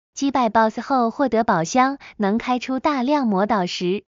击败Boss后获得宝箱.MP3